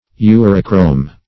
Search Result for " urochrome" : The Collaborative International Dictionary of English v.0.48: Urochrome \U"ro*chrome\, n. [1st uro- + Gr.